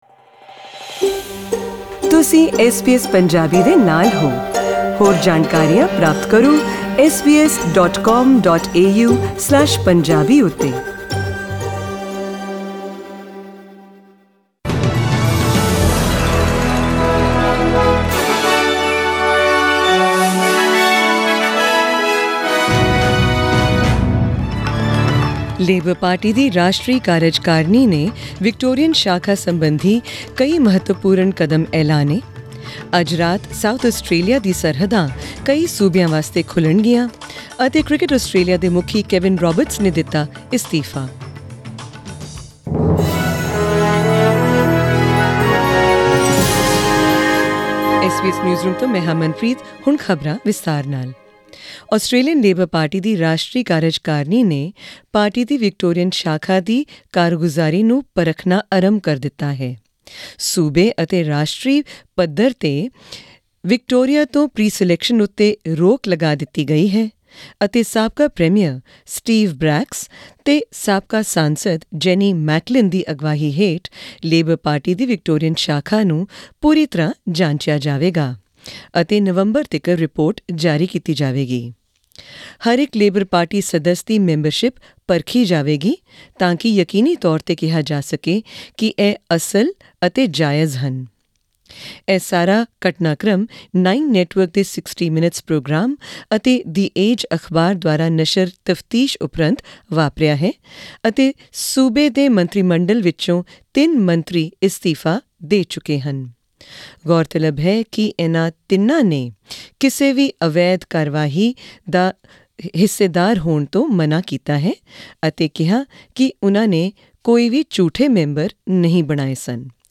Presenting news from Australia and around the world in tonight's SBS Punjabi news bulletin.